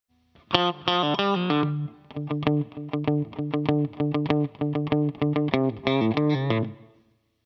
In each file I recorded the same phrase with a looper: first with the Original MXR DynaComp inserted at the beginning of the chain and a second recording with the Kemper Stomps.
Some Audio tests (first the Original MXR and second the KPA):
DEMO-DYNACOMP-ORIG-6.mp3